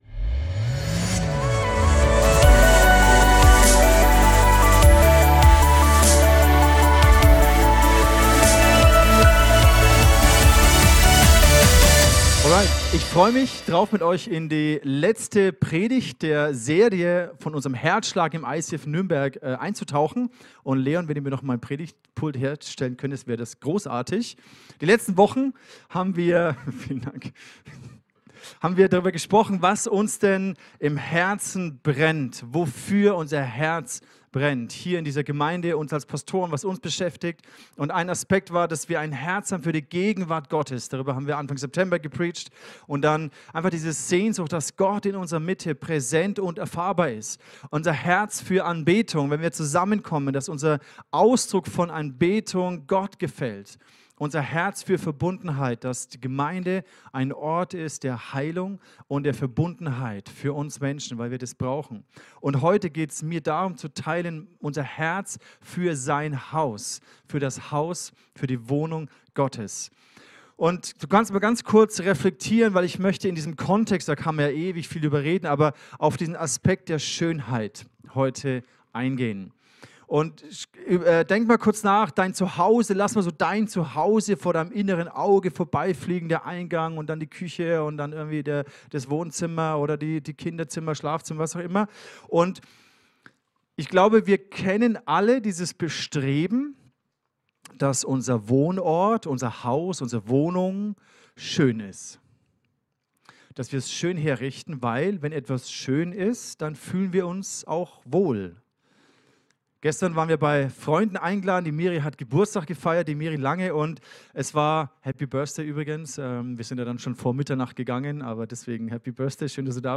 ICF Nürnberg Predigten